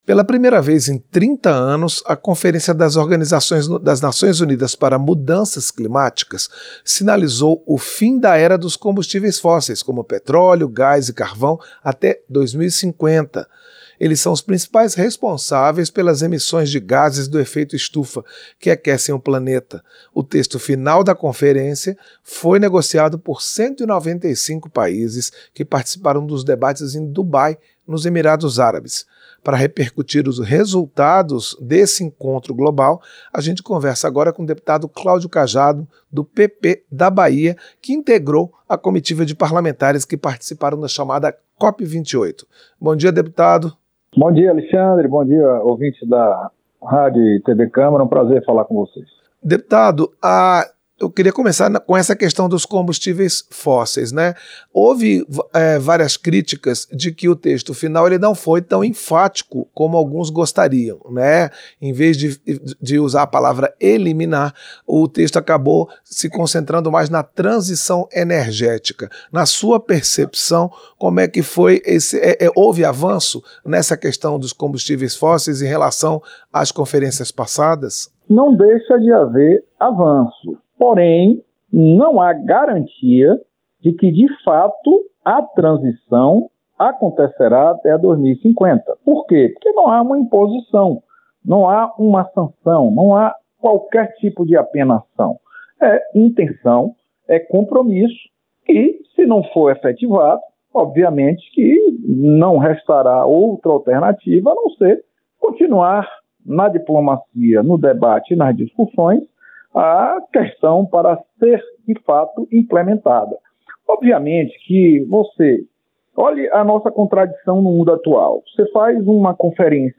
Entrevista - Dep. Claudio Cajado (PP-BA)